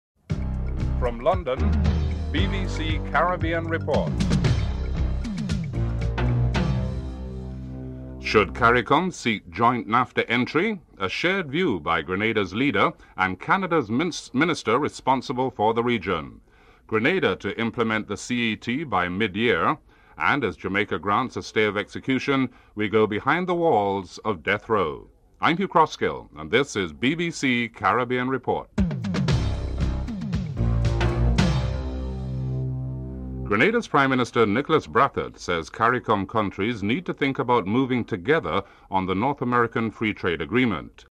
In an interview, Nicholas Brathwaite stated that CARICOM countries should move together on the issue of NAFTA. Christine Stewart argued that the Canadian government did not want to see any country suffer as a result of the trading agreement.
8. British Customs and Excise officials report on drug seizures coming into the UK (14:20-14:53)